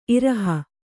♪ iraha